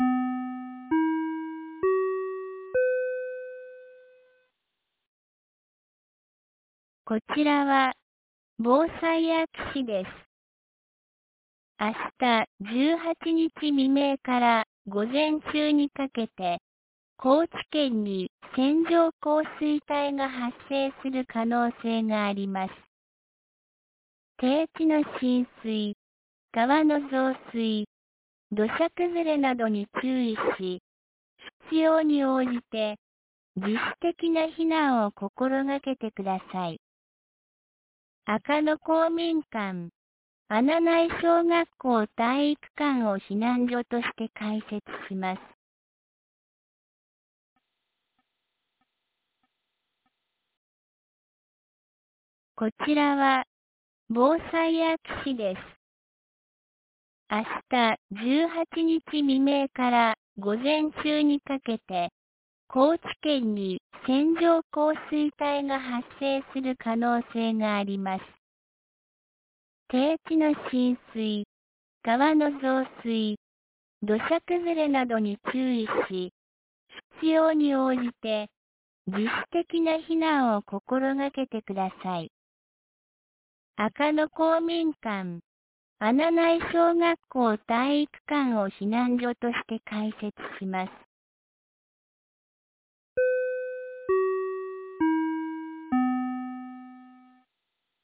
2024年06月17日 16時55分に、安芸市より穴内、赤野へ放送がありました。